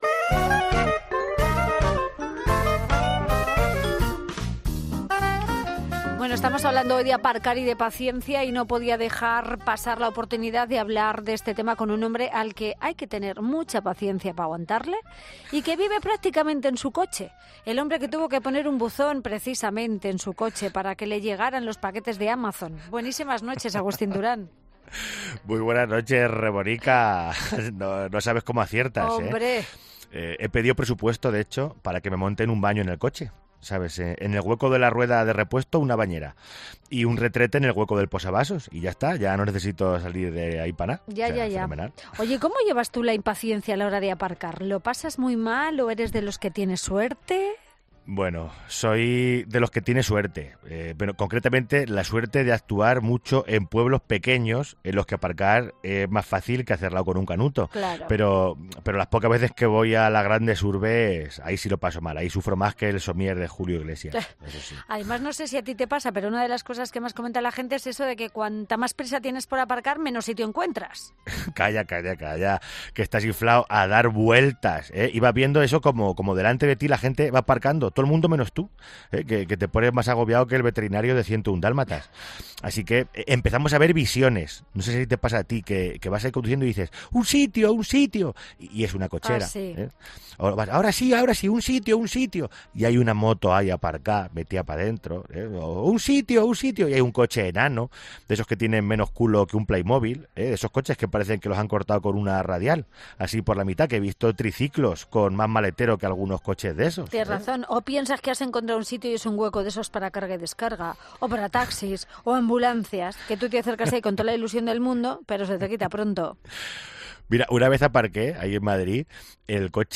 AUDIO: El cómico manchego nos trae su puntito de humor.